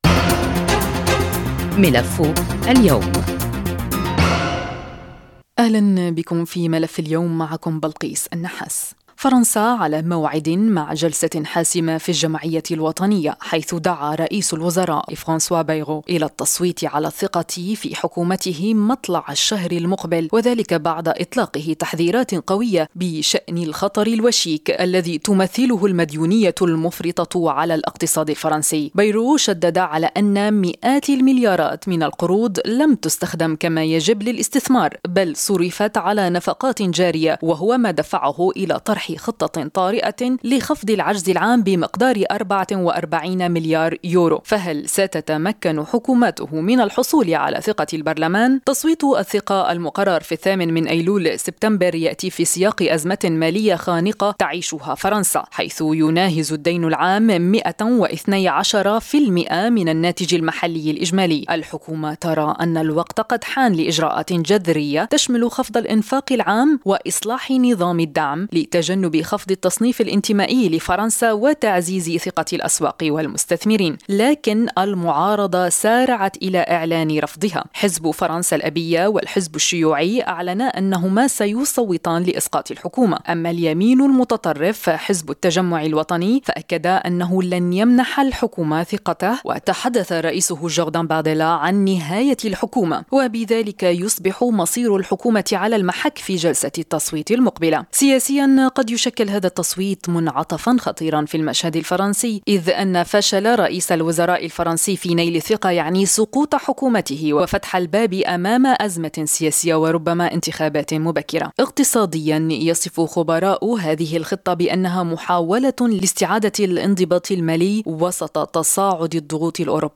وللحديث عن هذه التطورات نستضيف في ملف اليوم الصحفي والمحلل السياسي